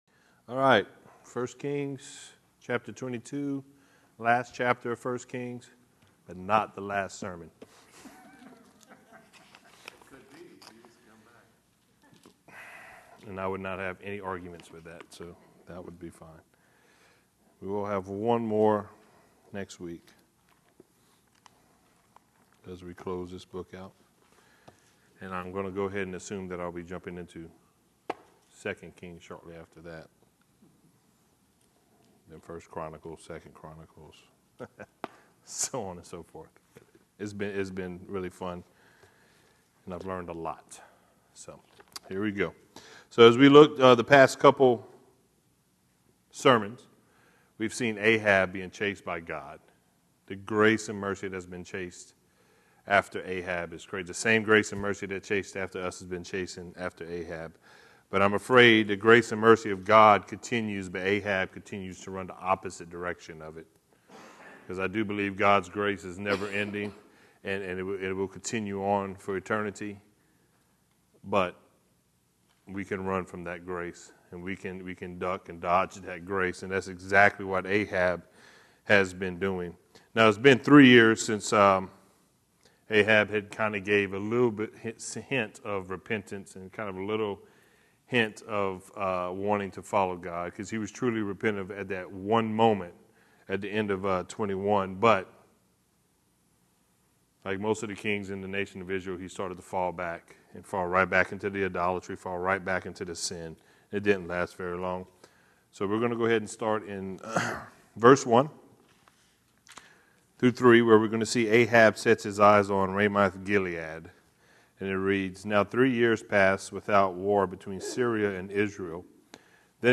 The book of 1 Kings is the sequel to 1 and 2 Samuel and begins by tracing Solomon’s rise to kingship after the death of David. The story begins with a united kingdom, but ends in a nation divided into 2 kingdoms. Join us for this verse by verse study